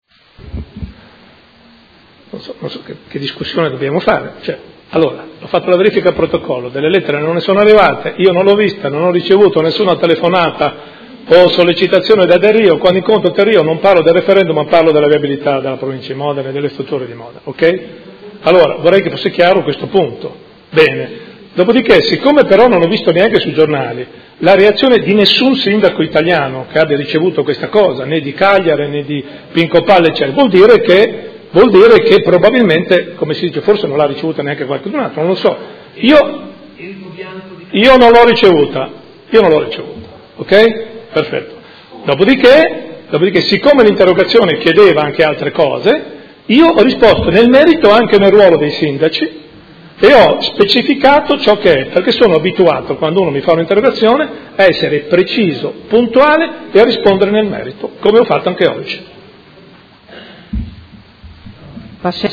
Seduta del 22/09/2016 Interrogazione del Consigliere Rocco (FAS-SI) e del Consigliere Campana (PerMeModena) avente per oggetto: Lettera del Ministro delle Infrastrutture Del Rio. Conclusioni